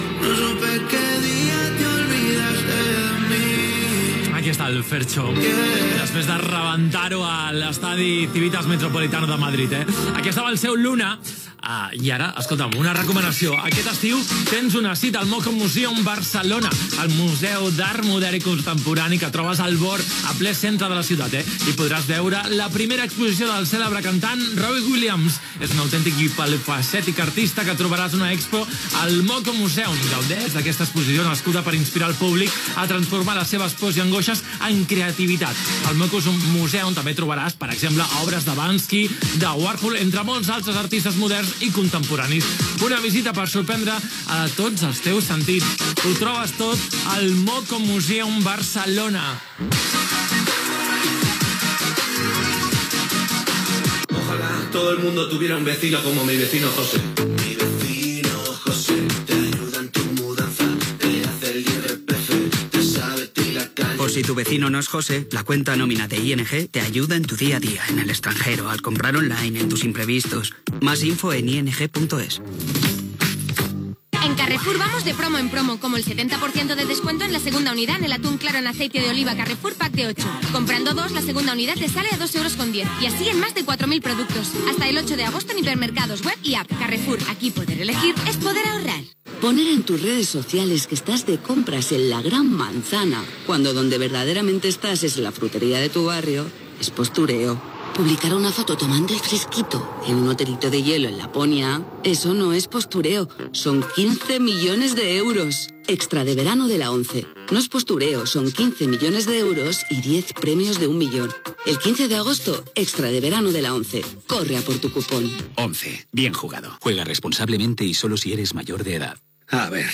Tema musical, publicitat, indicatiu de l'emissora, publicitat, indicatiu, tema musical
FM